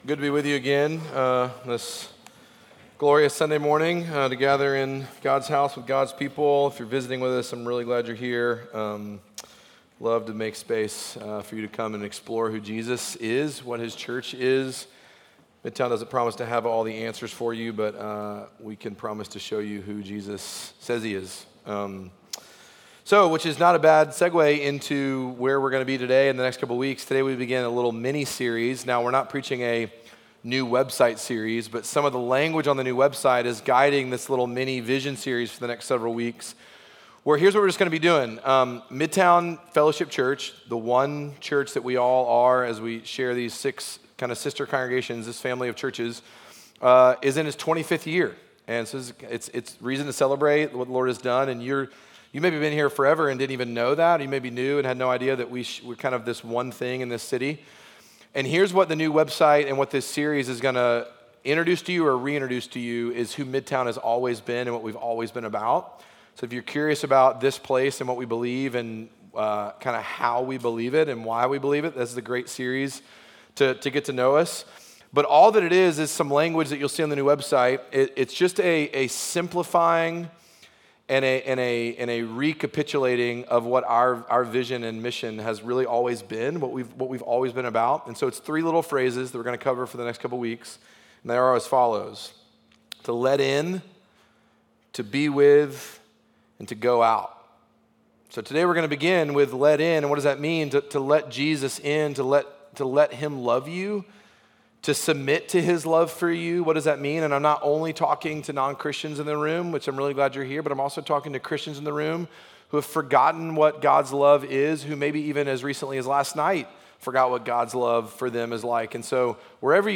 Midtown Fellowship 12 South Sermons Let In Aug 17 2025 | 00:42:35 1x 00:00 / 00:42:35 Subscribe Share Apple Podcasts Spotify Overcast RSS Feed Share Link Embed